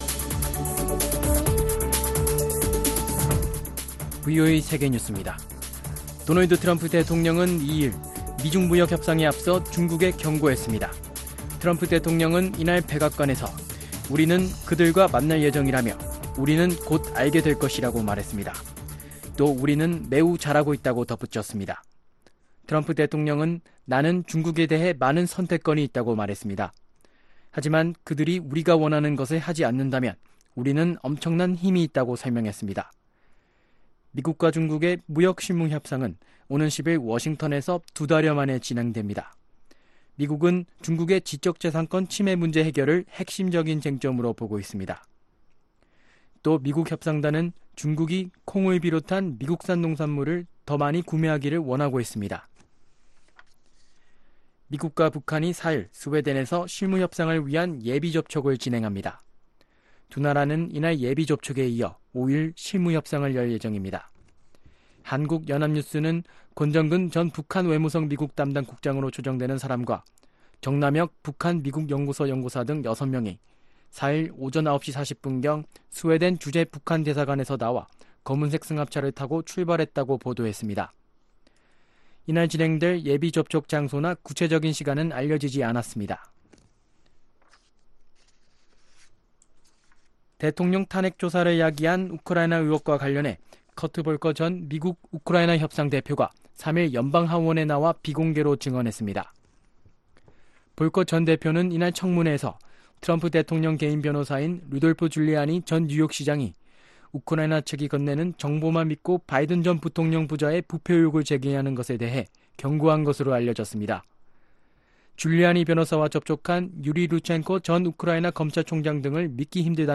VOA 한국어 아침 뉴스 프로그램 '워싱턴 뉴스 광장' 2019년 10월 5일 방송입니다. 미국 국방부가 북한의 미사일 발사가 잠수함이 아니라 수중 발사대에서 이뤄졌다고 확인했습니다. 미국은 북한 핵무기 제거가 아니라 핵무기로 인한 ‘위협’을 순차적으로 제거해 나가는데 우선 순위를 둬야 한다고 전 국무부 대북정책 특별대표가 밝혔습니다.